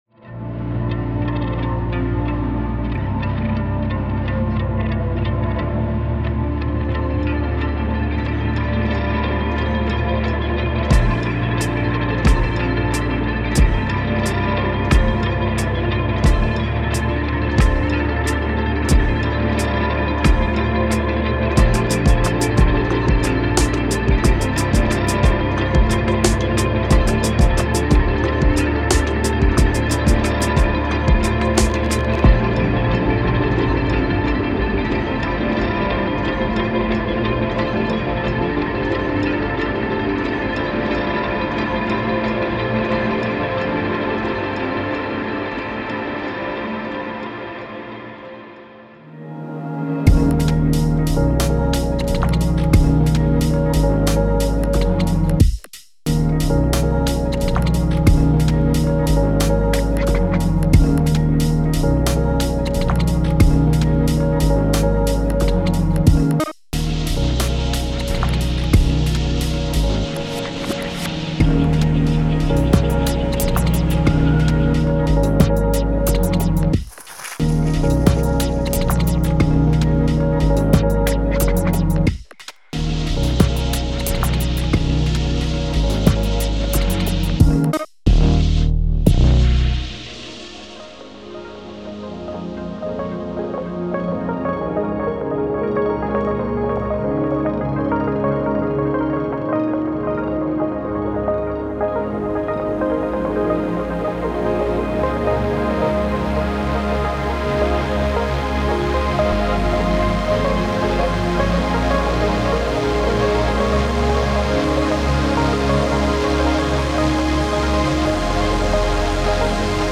Genre:Downtempo
すべてのサウンドは演奏され、録音され、キャラクター、モジュレーション、奥行きを保持するよう加工されています。
静的なループではなく微細な動きが必要なトラックに最適です。
洗練よりテクスチャー、完璧さよりも動きを重視しています。
デモサウンドはコチラ↓